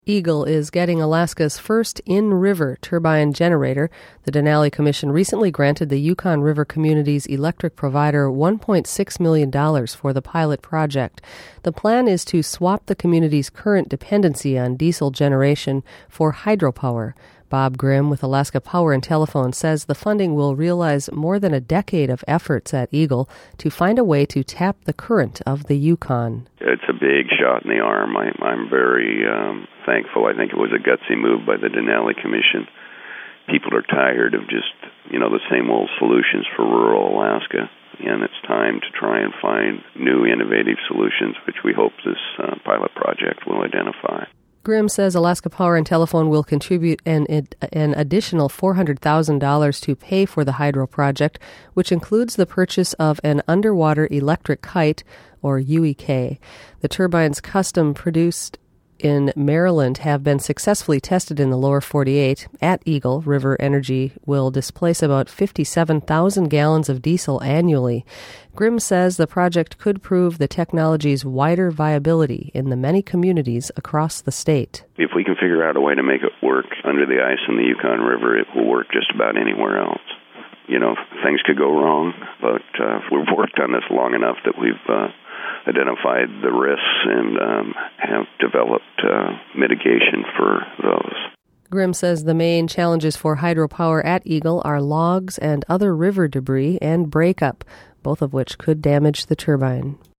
Eagle Hydrokinetic Turbine Interview